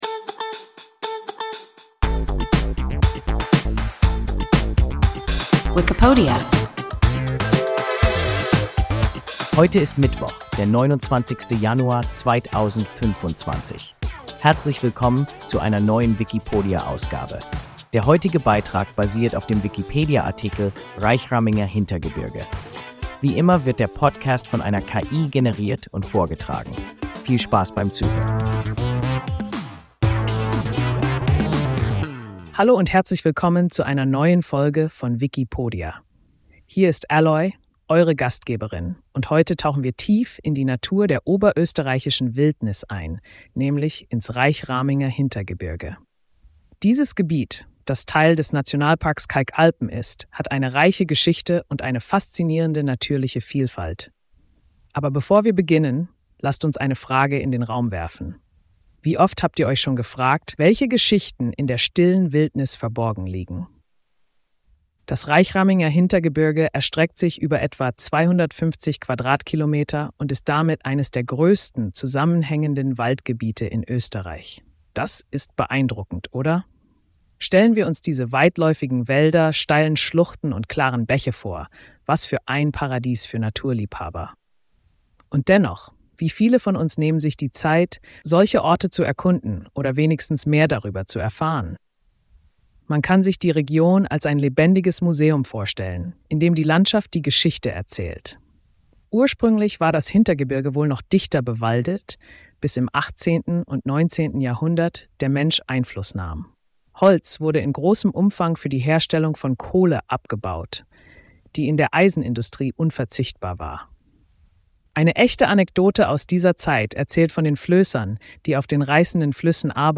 Reichraminger Hintergebirge – WIKIPODIA – ein KI Podcast